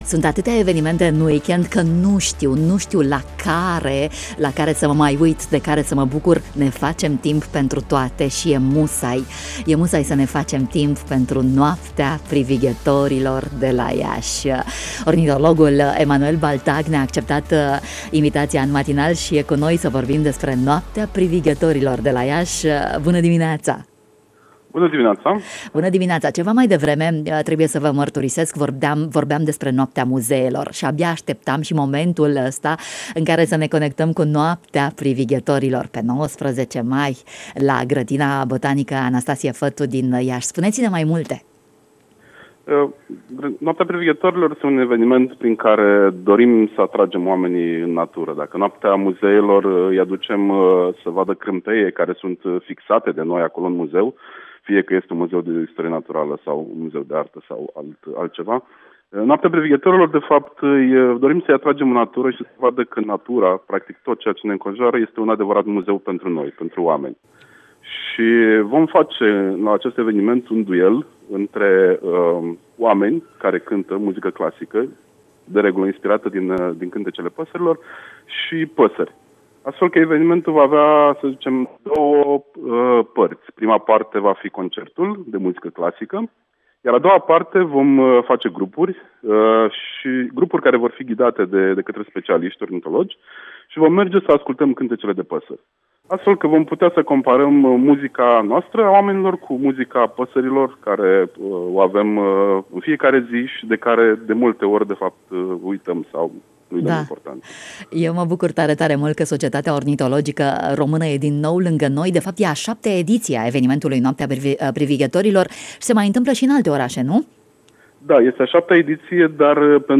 Mai multe detalii despre evenimentul de poveste, joi dimineață, la 8.20, în matinalul Radio România Iași. ?
În dialog cu